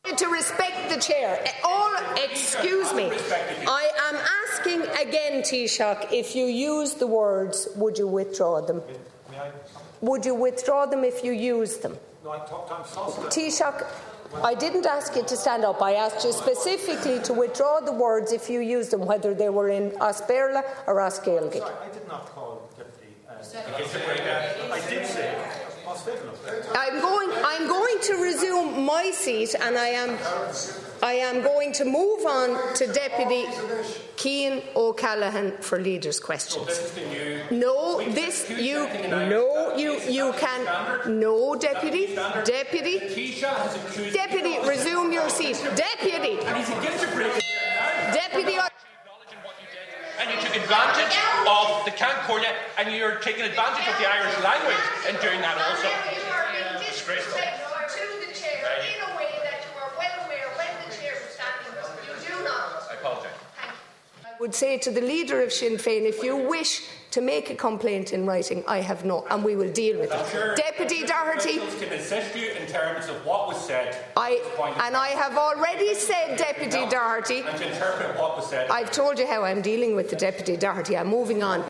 A row broke out in the Dail earlier after the Taoiseach was accused of using Irish to break the Dail rules.
Donegal Deputy Pearse Doherty accused the Taoiseach of ‘exploiting’ the fact Ceann Comhairle Verona Murphy doesn’t speak Irish: